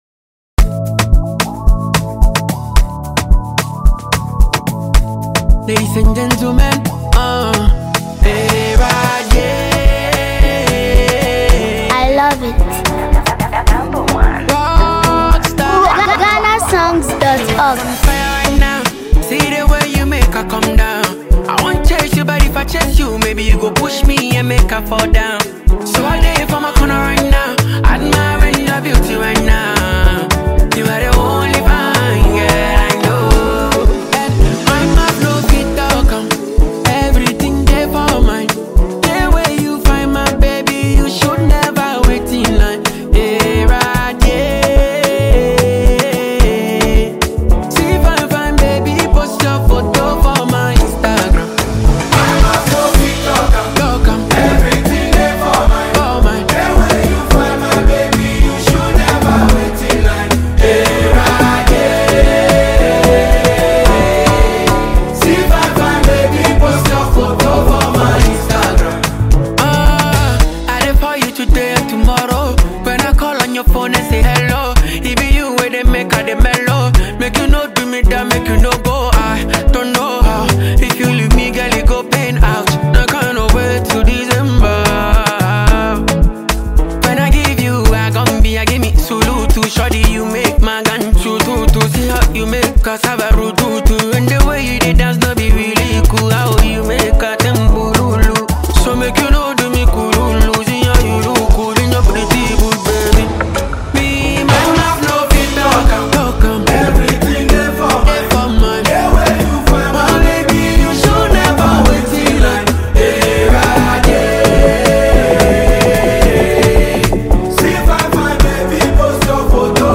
Ghanaian Afrobeat and highlife